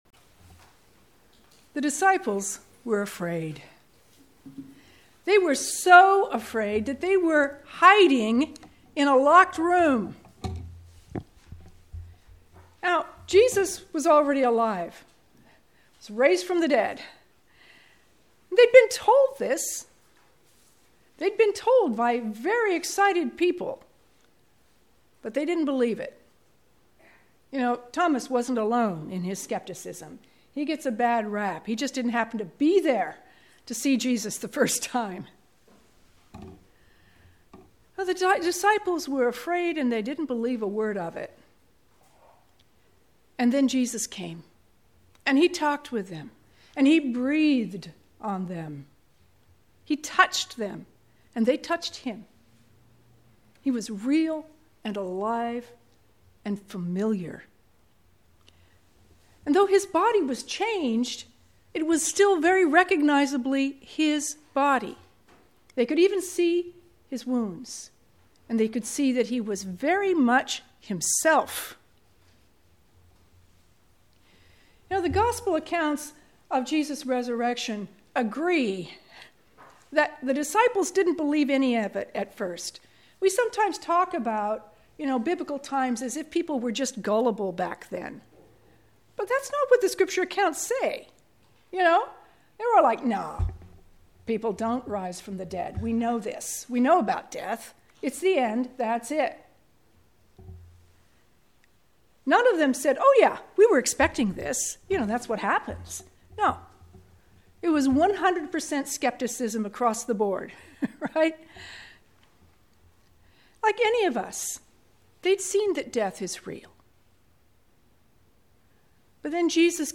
Sermons, St. Stephen Episcopal Church, Newport, Oregon